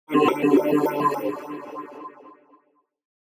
skill.mp3